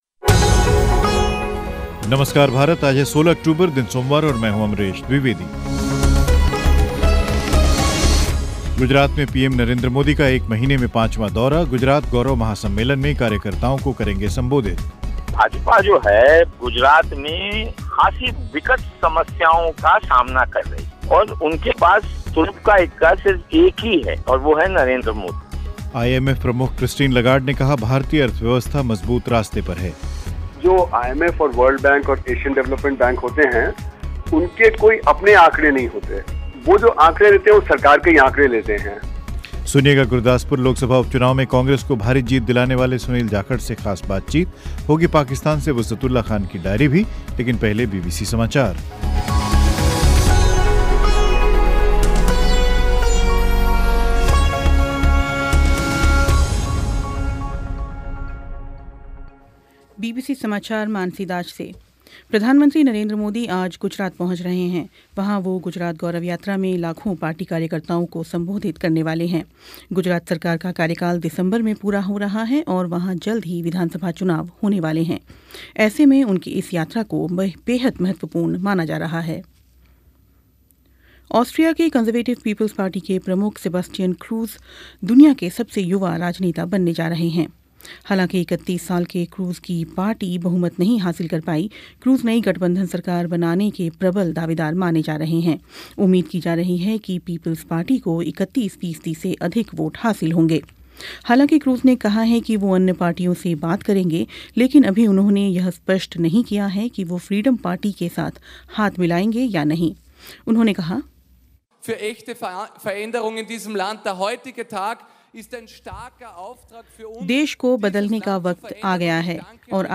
आईएमएफ़ प्रमुख क्रिस्टीन लगार्ड ने कहा भारतीय अर्थव्यवस्था मज़बूत रास्ते पर है सुनिए गुरदासपुर लोकसभा उपचुनाव में कांग्रेस को भारी जीत दिलानेवाले सुनील जाखड़ से ख़ास बातचीत